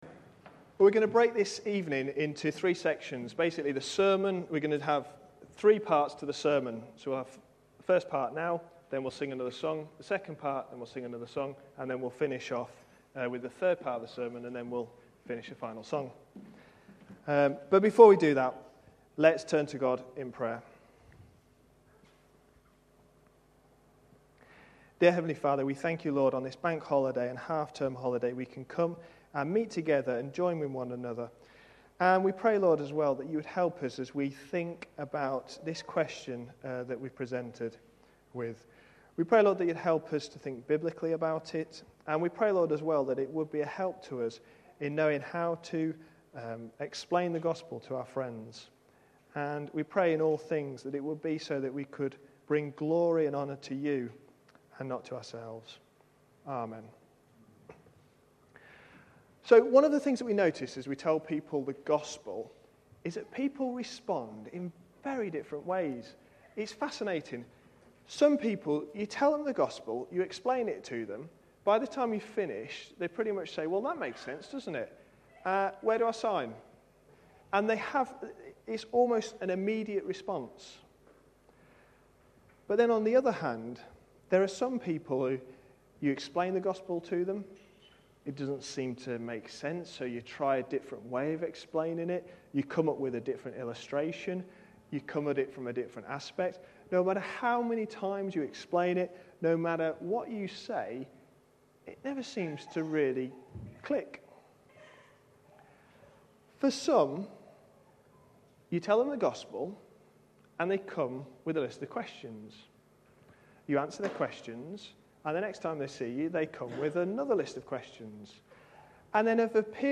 A sermon preached on 29th May, 2011.